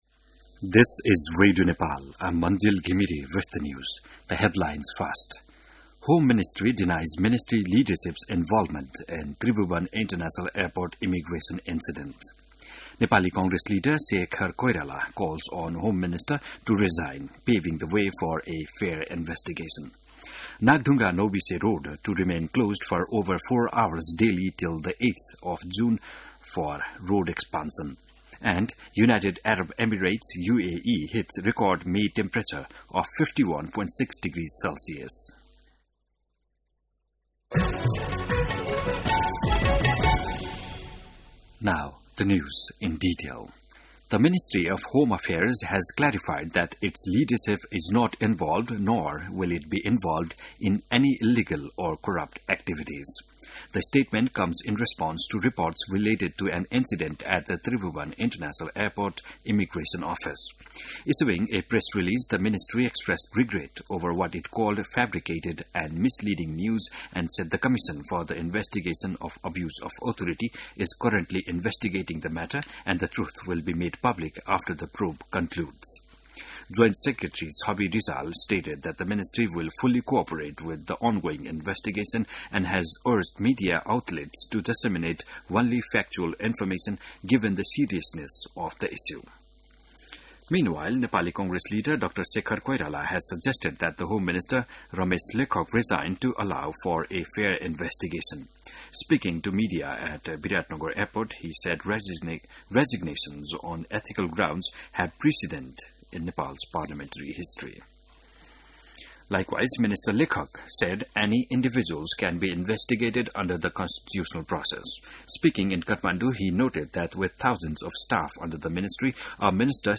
बिहान ८ बजेको अङ्ग्रेजी समाचार : ११ जेठ , २०८२